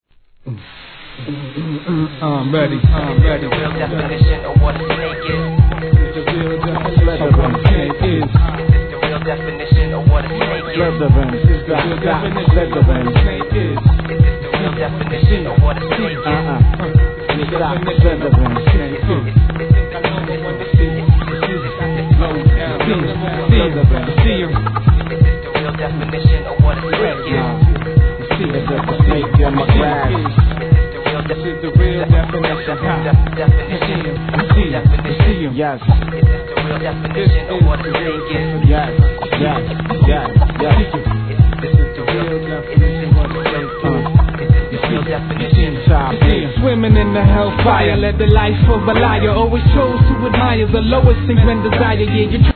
HIP HOP/R&B
シンプルながら煙たくもFATなプロダクション、たまりません!!